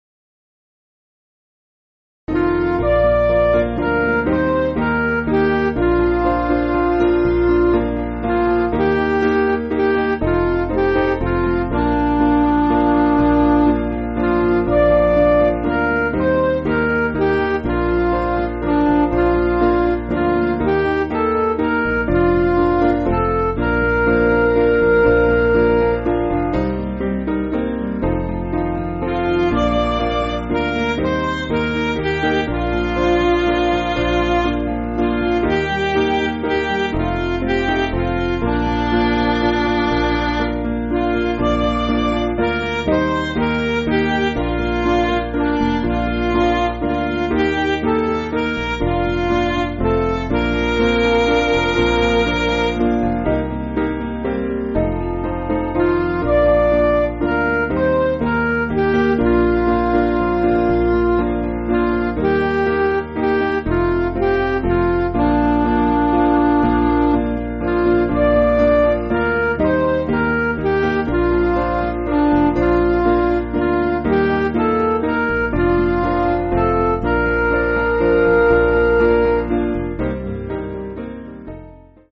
Piano & Instrumental
(CM)   4/Bb